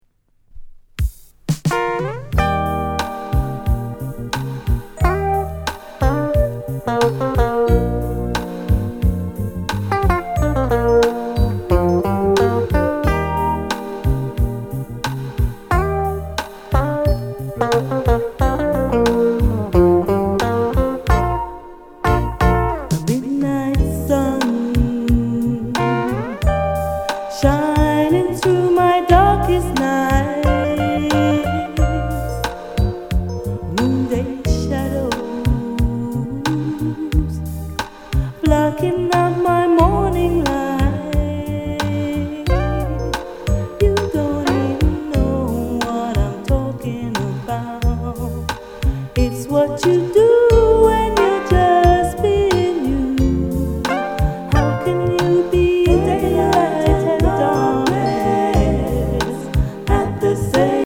SWEET LOVERS ROCK